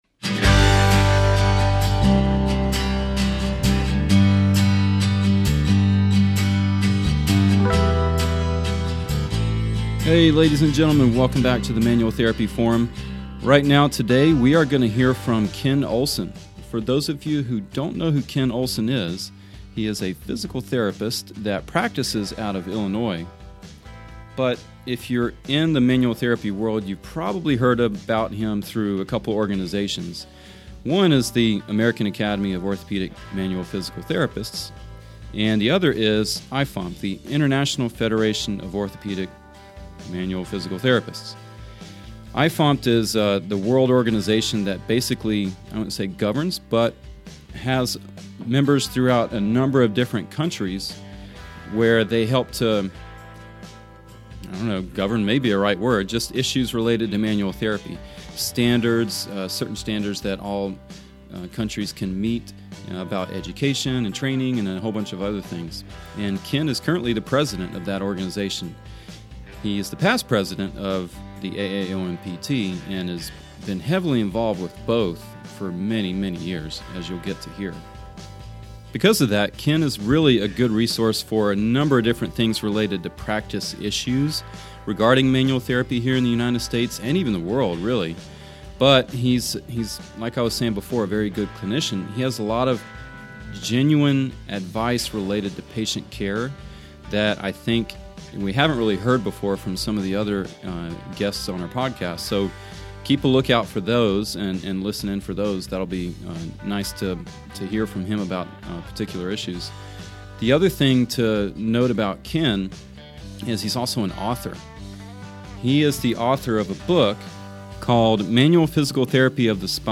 This conversation
You will also notice rather quickly that he is a wonderful story-teller.